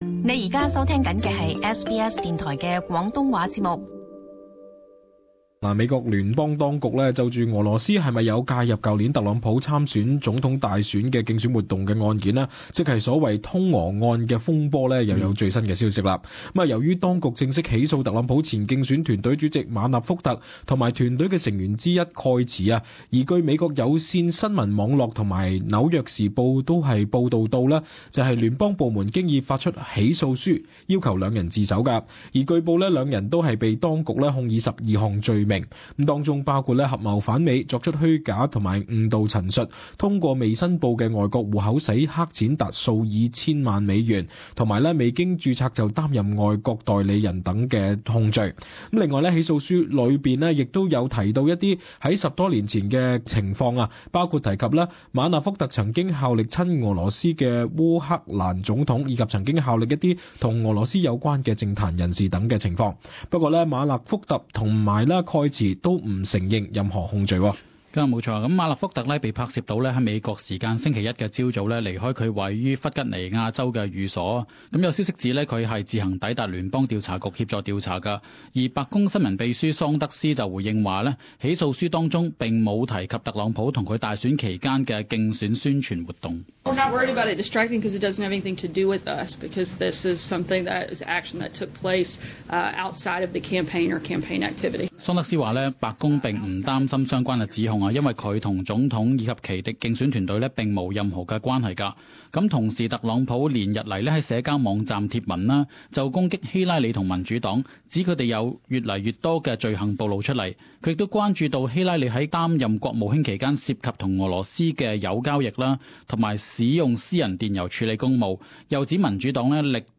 【时事报导】「通俄门」风波：特朗普前竞选成员否认控罪